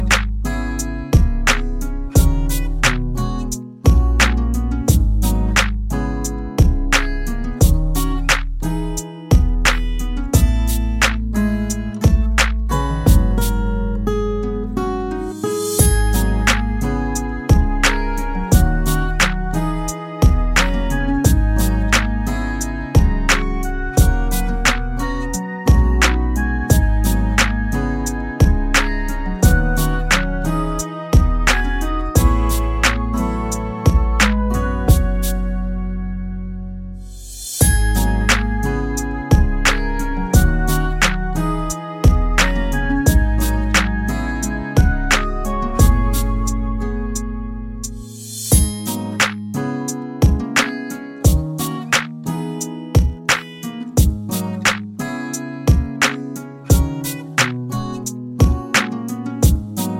no Backing Vocals R'n'B / Hip Hop 4:15 Buy £1.50